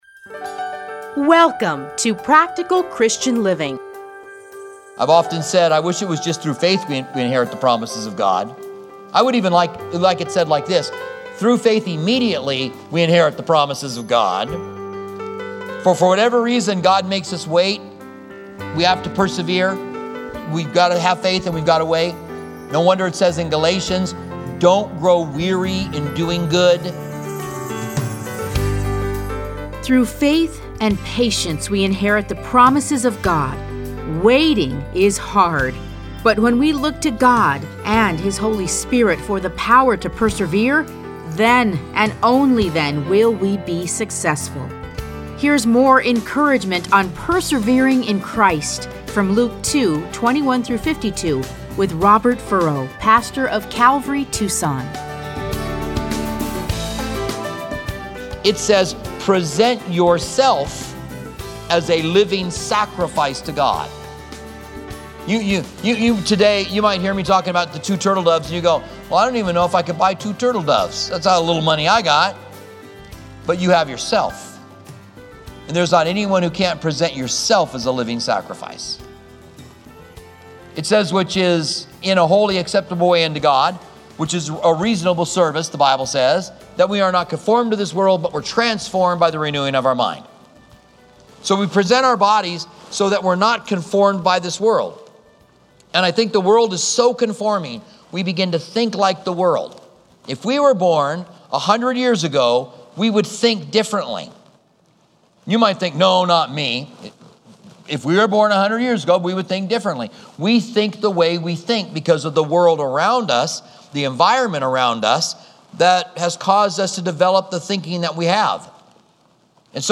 Listen to a teaching from Luke 2:21-52.